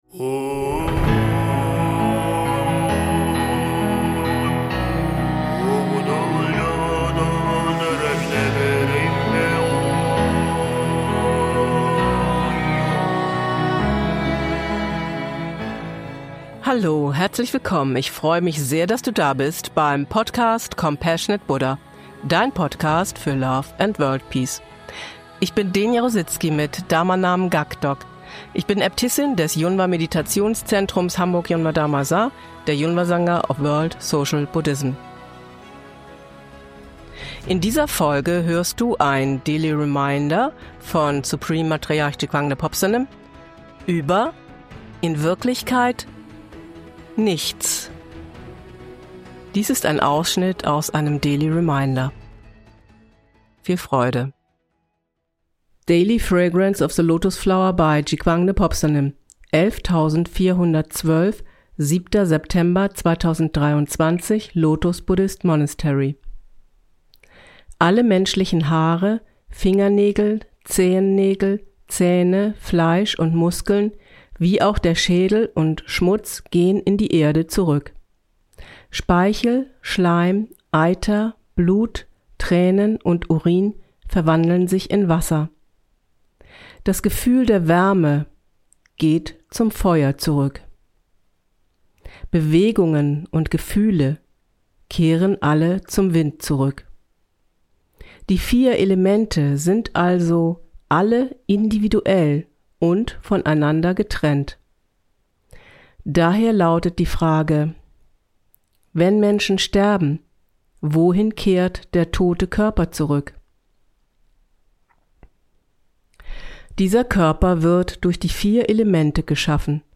Intro/Outro music